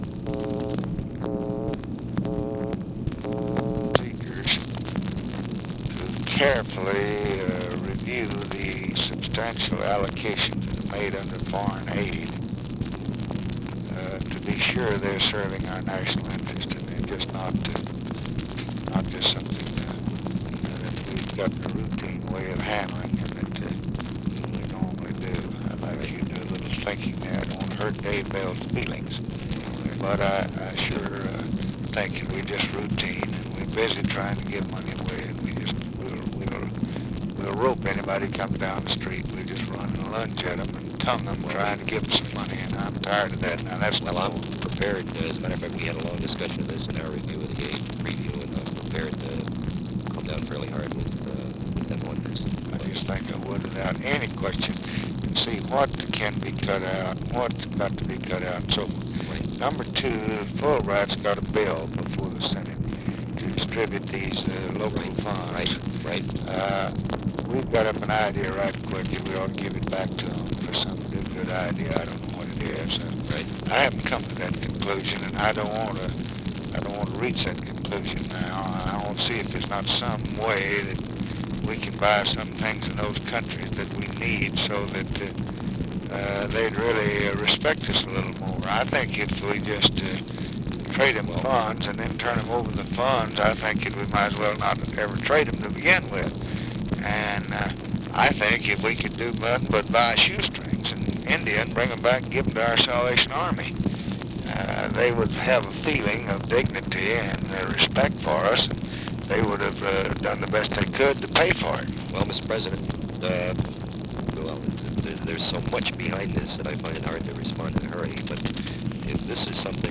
Conversation with CHARLES SCHULTZE, June 28, 1965
Secret White House Tapes